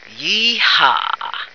F2yeehaw.ogg